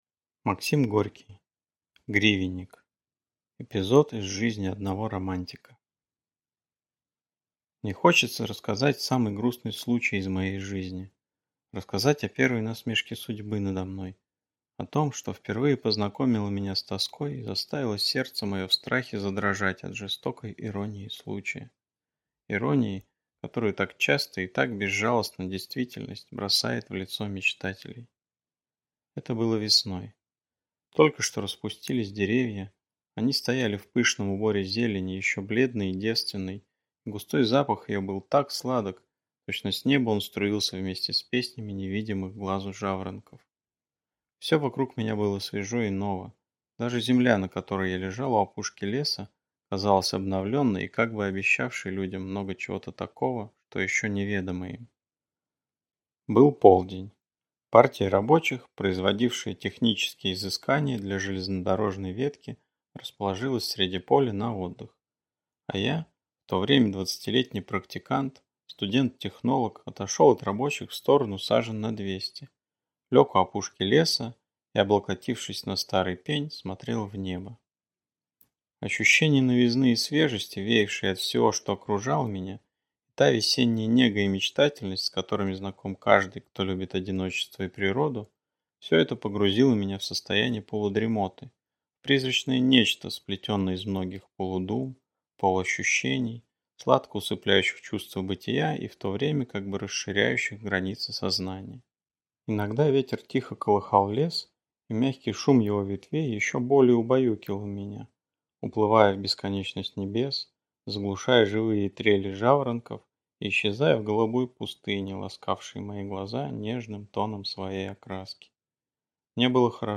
Аудиокнига Гривенник | Библиотека аудиокниг